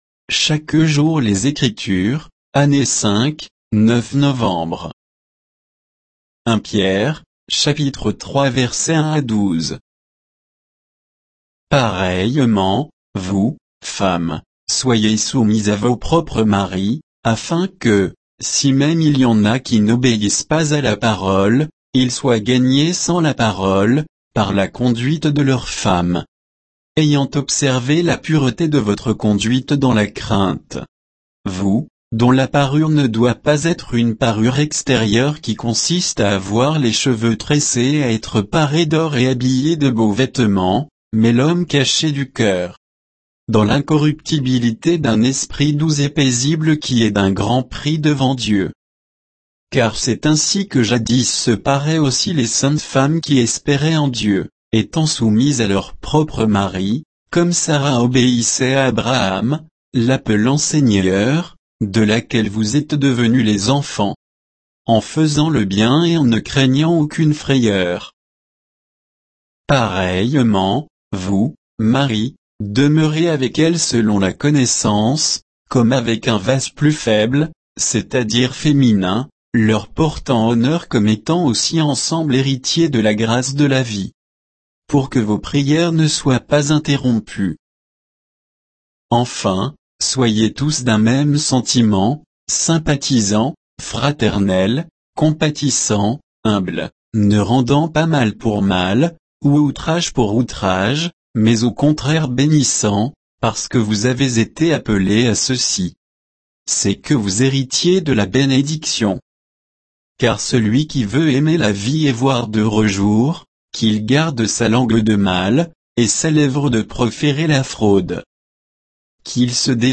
Méditation quoditienne de Chaque jour les Écritures sur 1 Pierre 3